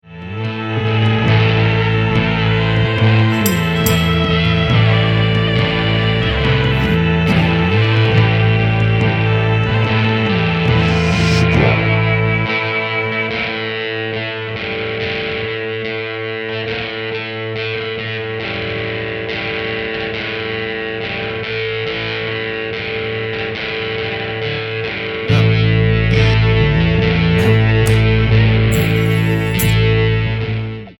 rhythm guitars, noises
lead guitar, keys, noises